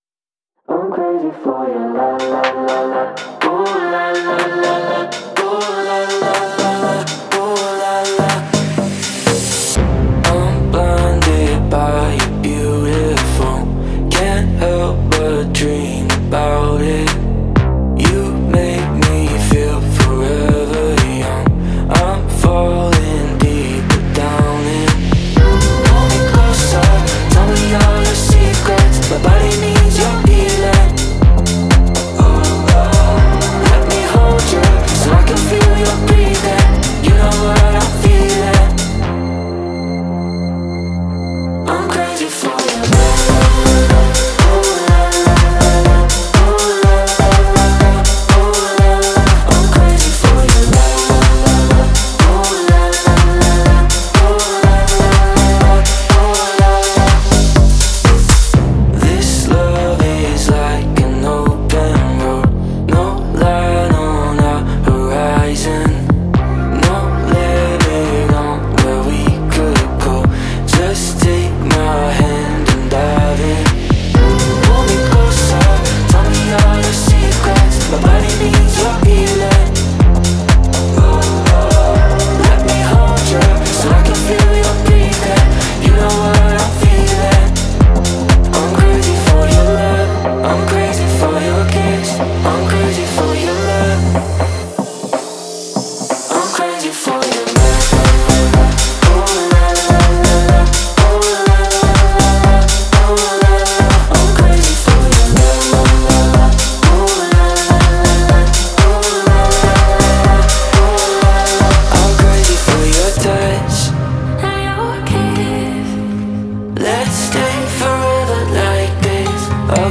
это зажигательная композиция в жанре хаус